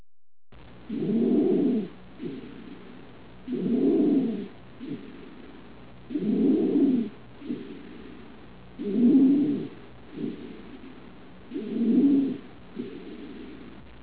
3_3_Stridoros_legzes-stridorous_breathing.wav